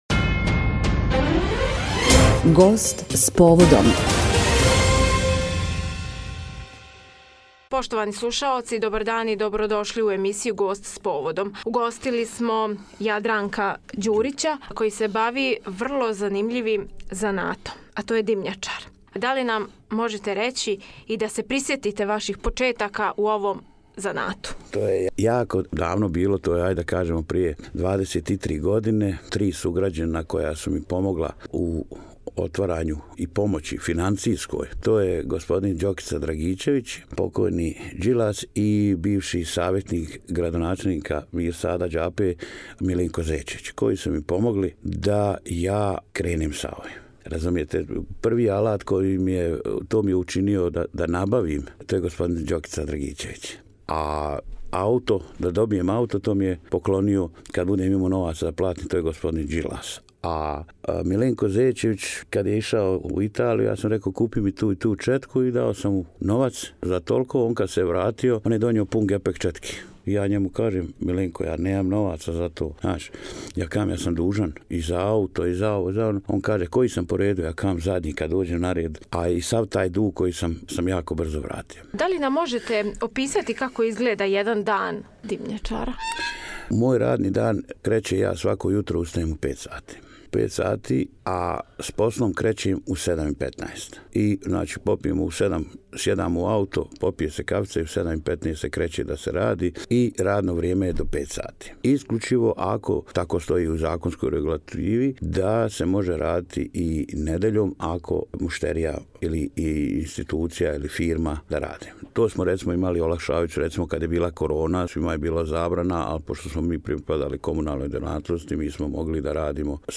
У емисији "Гост с поводом" - димњачар